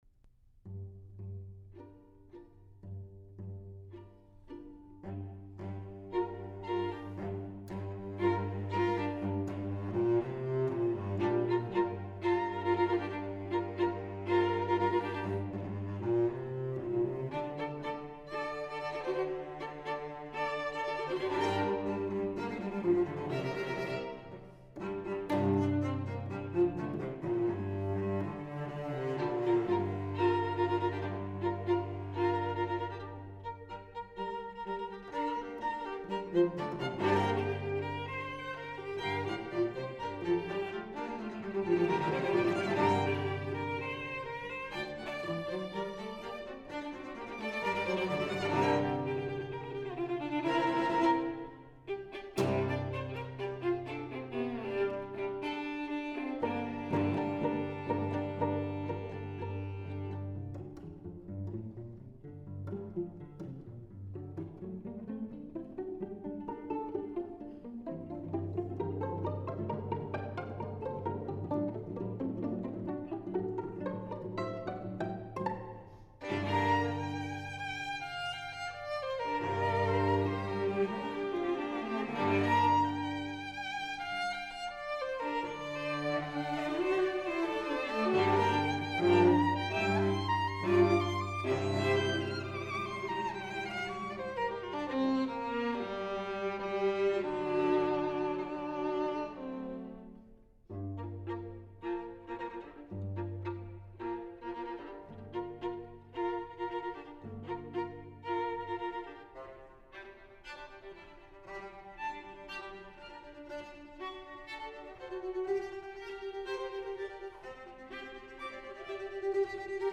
for String Trio (2011)
is an energetic, rhythmic movement.